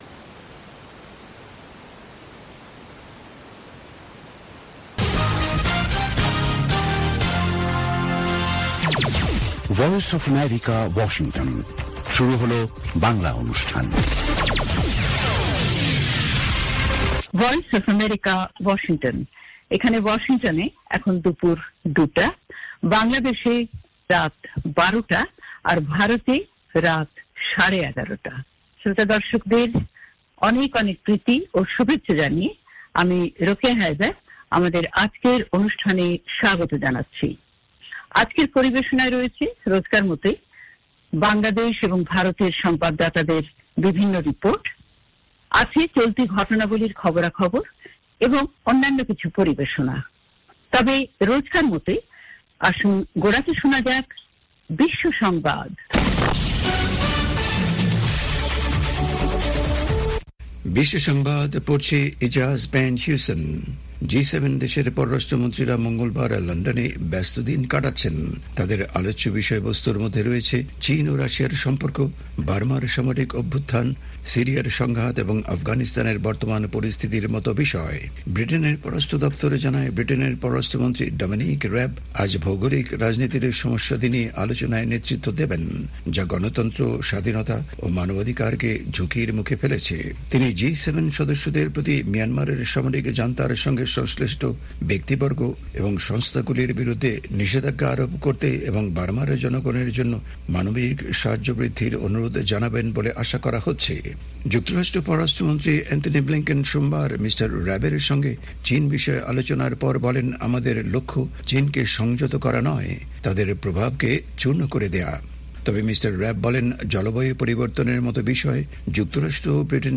অনুষ্ঠানের শুরুতেই রয়েছে আন্তর্জাতিক খবরসহ আমাদের ঢাকা এবং কলকাতা সংবাদদাতাদের রিপোর্ট সম্বলিত বিশ্ব সংবাদ, বুধবারের বিশেষ আয়োজন হ্যালো ওয়াশিংটন। আর আমাদের অনুষ্ঠানের শেষ পর্বে রয়েছে যথারীতি সংক্ষিপ্ত সংস্করণে বিশ্ব সংবাদ।